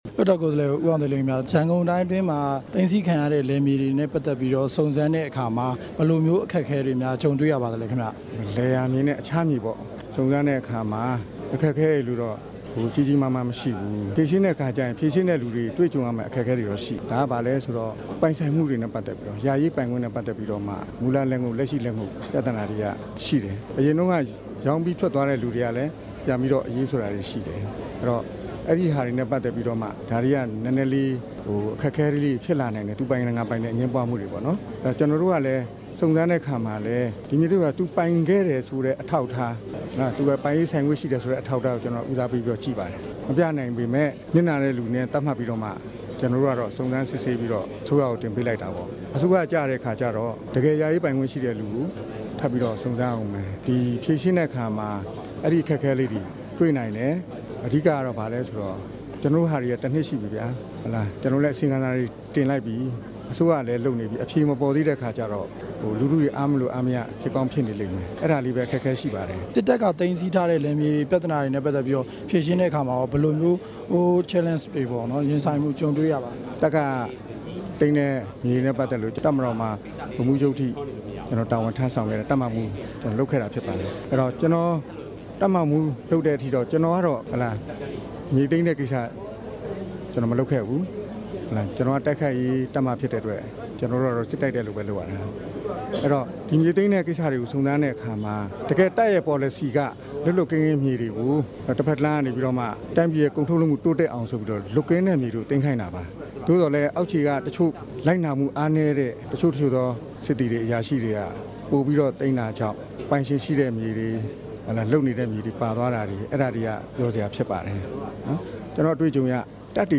ဦးအောင်သိန်းလင်းနဲ့ မေးမြန်းချက်